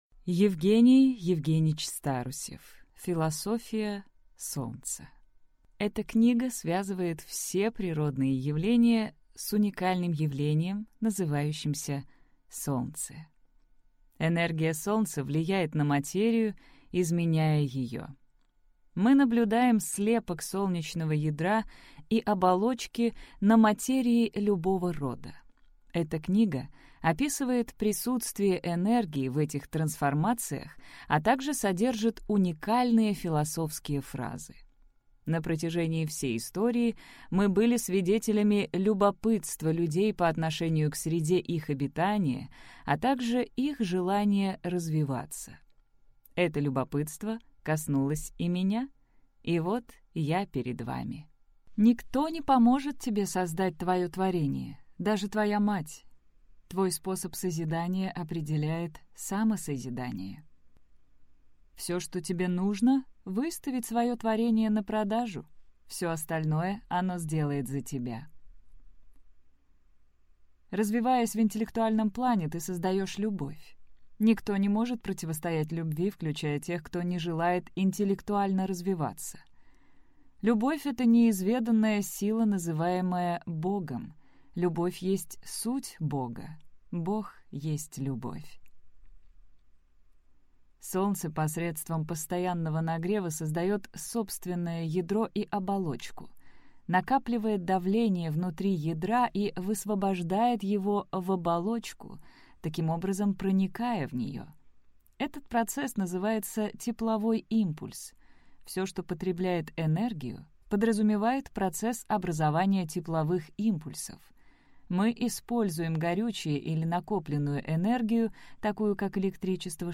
Аудиокнига Философия Солнца | Библиотека аудиокниг